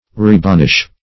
Rebanish \Re*ban"ish\ (r[=e]*b[a^]n"[i^]sh)